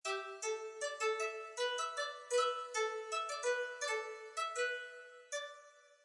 ambient_sound.mp3